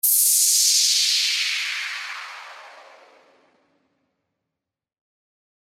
FX-974-WHOOSH
FX-974-WHOOSH.mp3